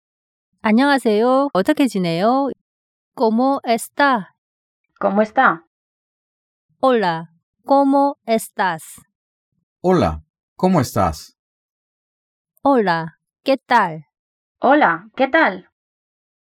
¿Cómo está? l꼬모 에스따l
Hola, ¿cómo estás? l올라 꼬모 에스따스l
Hola, ¿qué tal? l올라 께딸l